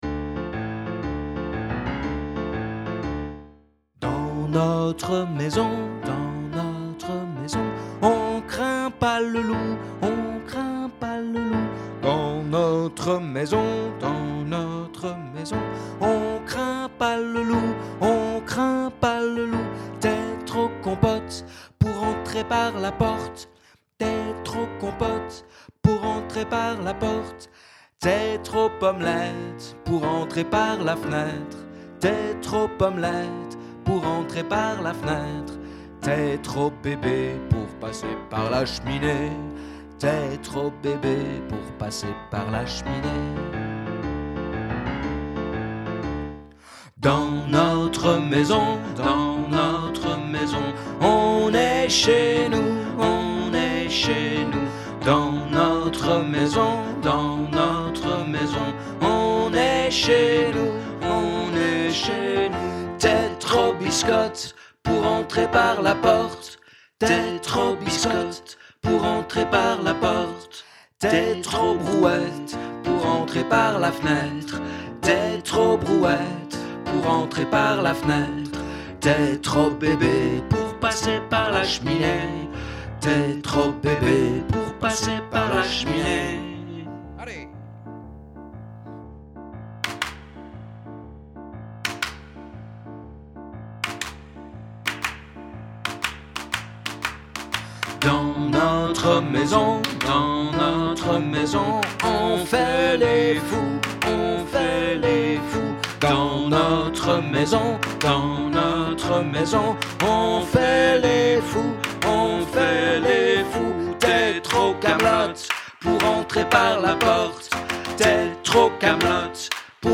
Mélodie complète
comptine_complet.mp3